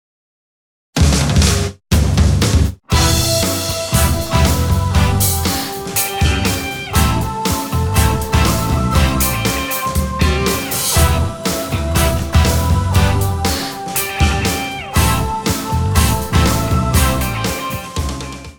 迪斯科……这是闹那样
居然动次打次起来了……
这明显就是电子月啊，感觉不错的样子